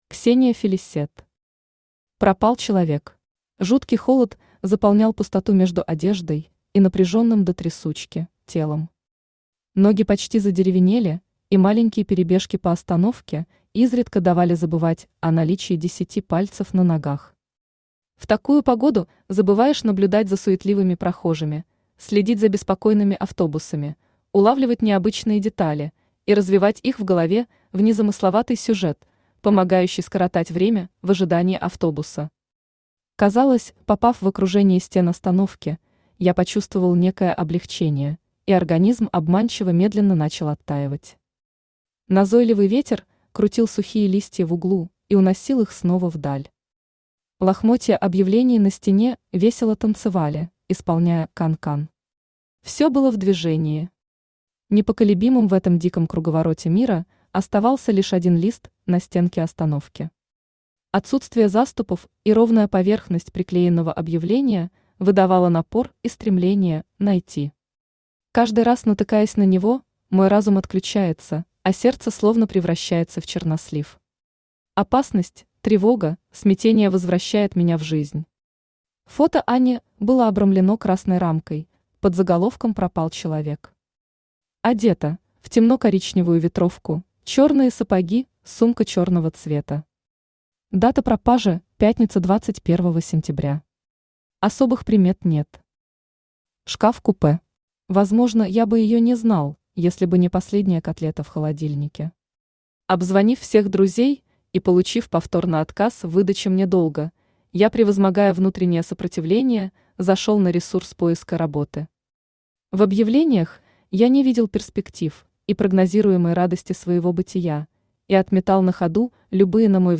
Аудиокнига Пропал человек | Библиотека аудиокниг
Aудиокнига Пропал человек Автор Ксения Фелисетт Читает аудиокнигу Авточтец ЛитРес. Прослушать и бесплатно скачать фрагмент аудиокниги